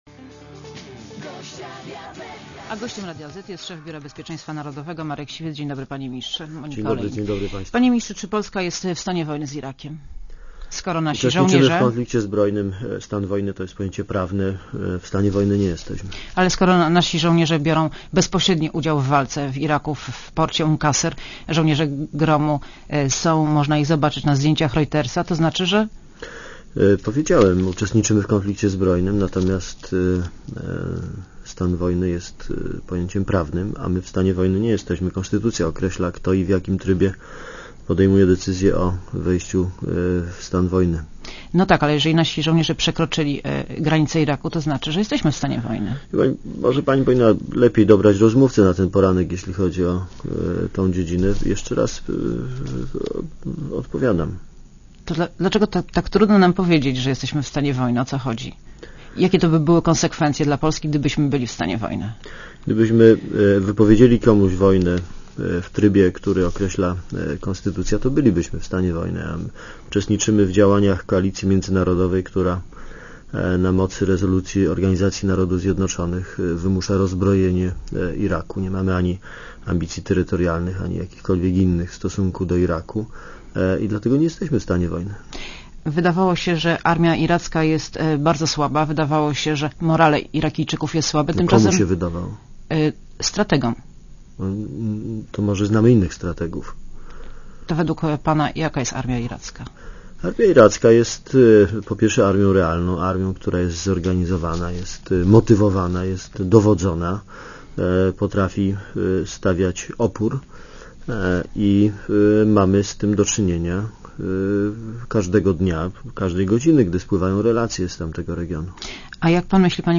Monika Olejnik rozmawia z Markiem Siwcem - szefem Biura Bezpieczeństwa Narodowego